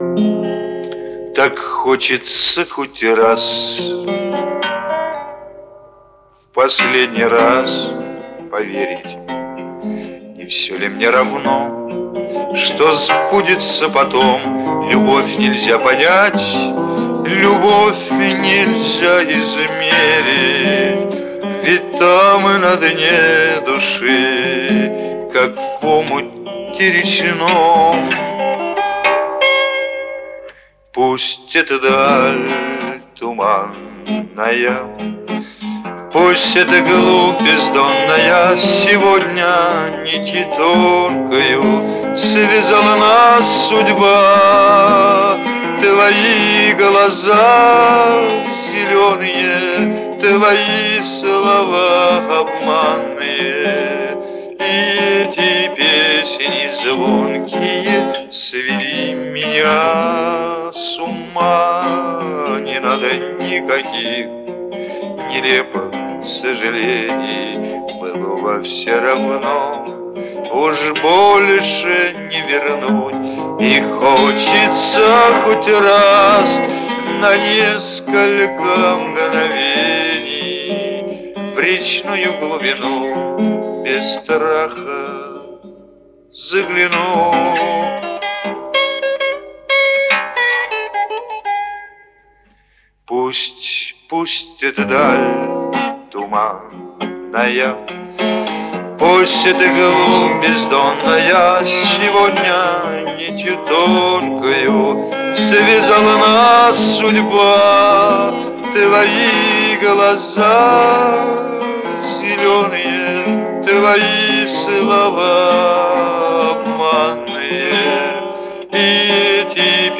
вот песенка ВАМ от меня.........романс.(Твои глаза зелёные)